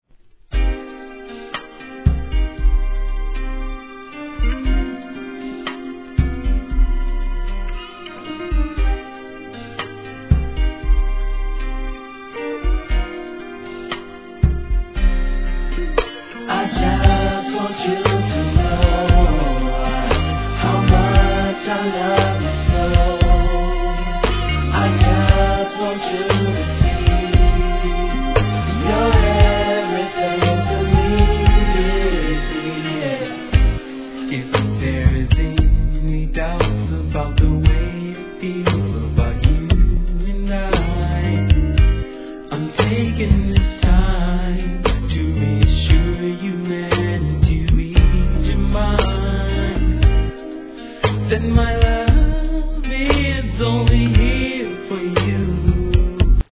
G-RAP/WEST COAST/SOUTH
1996年のミディアム好FUNK!